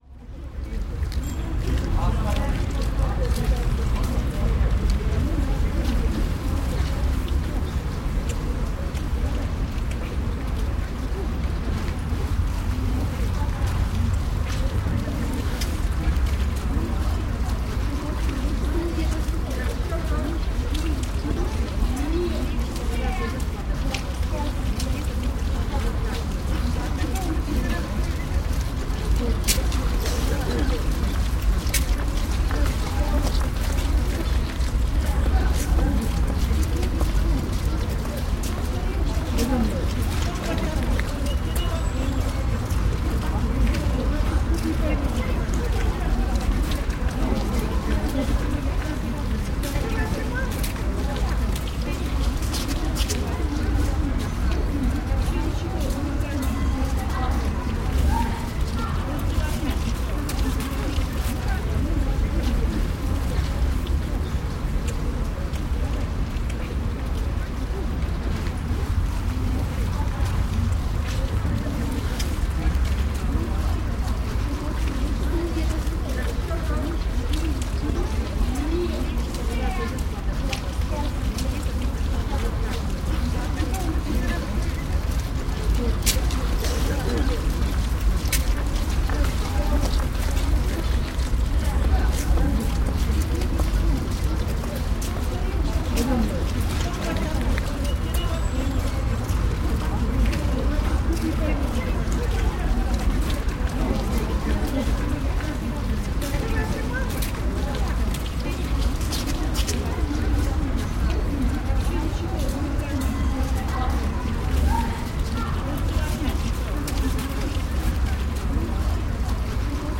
На этой странице собраны разнообразные звуки рынка: гул толпы, крики продавцов, стук товаров, смех покупателей.
Атмосферные звуки рынков России